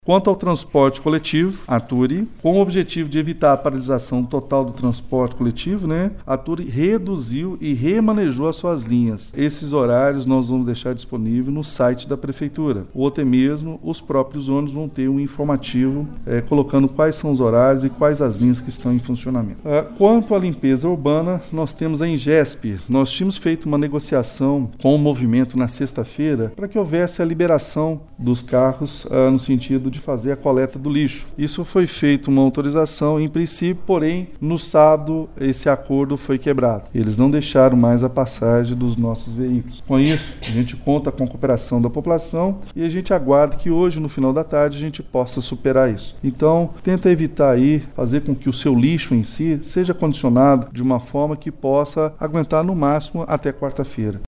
Na tarde desta segunda-feira, 28 de maio, o prefeito Elias Diniz (PSD) disse que a administração municipal tomou várias para que os serviços públicos funcionem em Pará de Minas.